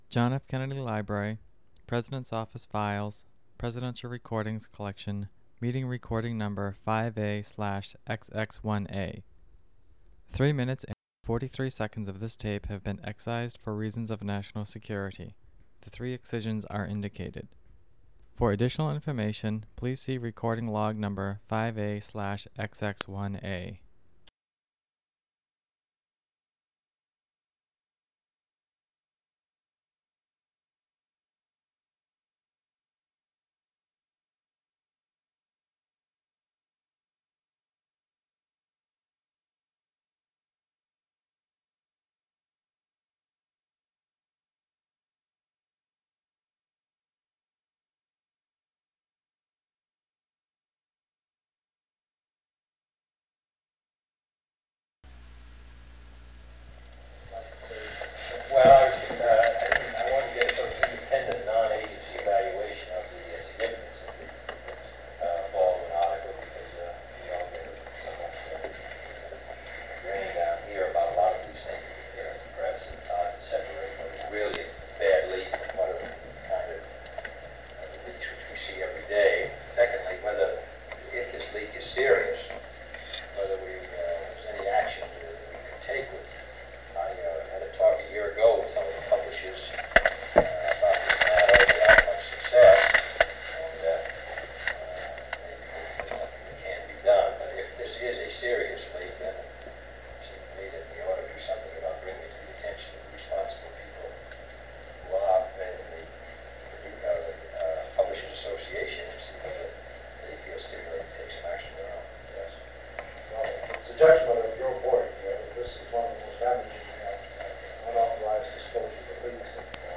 Secret White House Tapes | John F. Kennedy Presidency Meeting with the President’s Foreign Intelligence Advisory Board Rewind 10 seconds Play/Pause Fast-forward 10 seconds 0:00 Download audio Previous Meetings: Tape 121/A57.